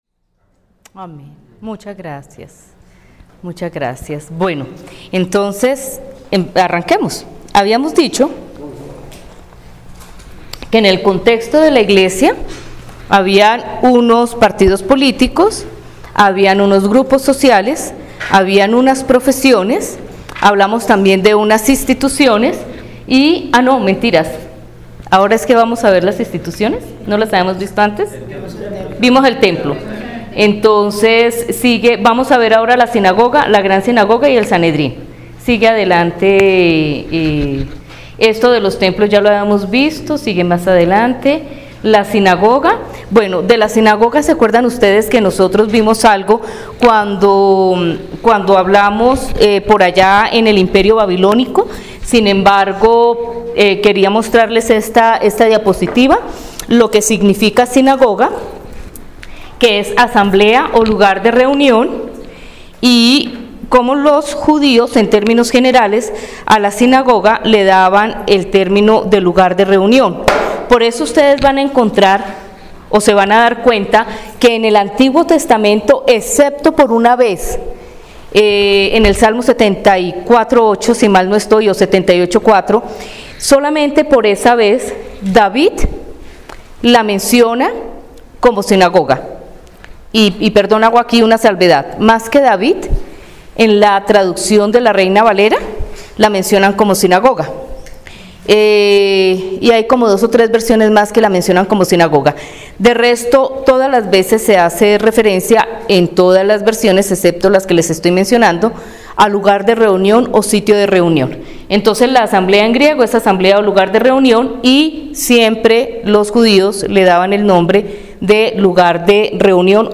Lección 1: Introduccion a la historia del cristianismo VII (Marzo 24, 2018)